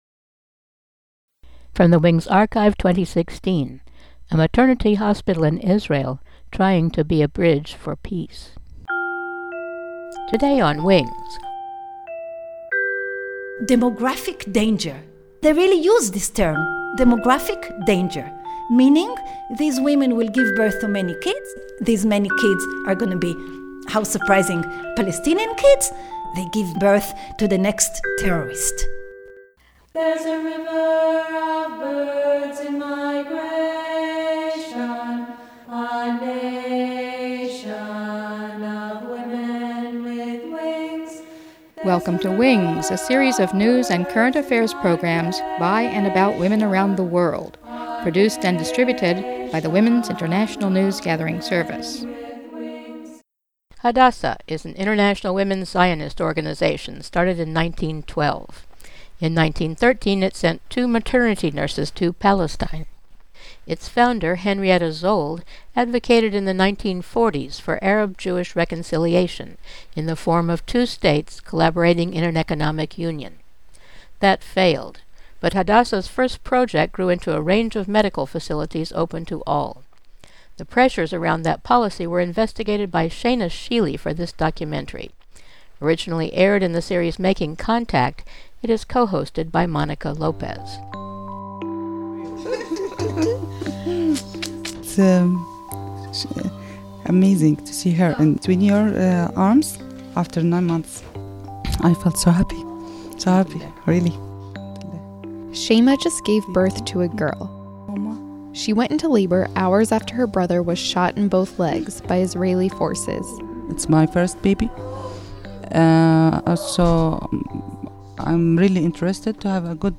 Hadassah Hospital in Jerusalem was founded on a promise to serve all patients with the same, excellent care. But the conditions of occupation and resistance in the region undermined that intention, sowing discrimination and fear. This documentary looks deeply at how these played out in the maternity ward, among patients and potential patients, and members of the staff.